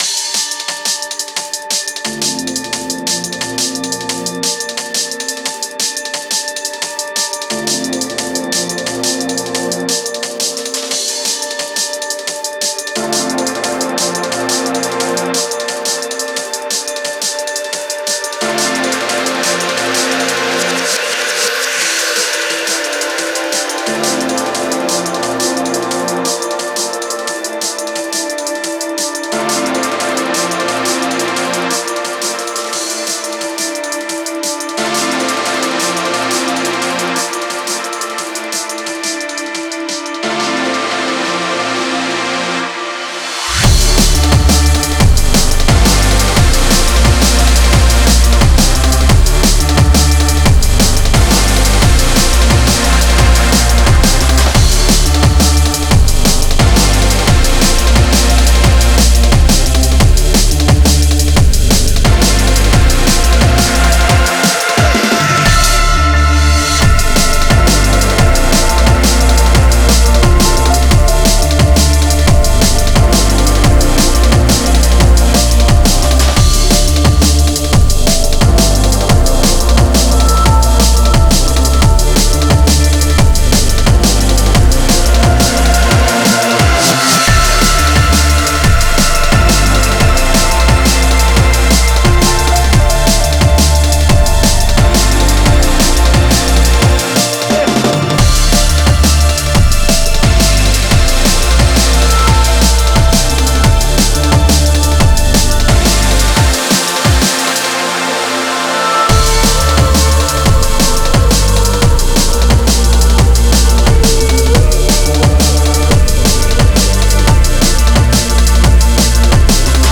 Style: Drum & Bass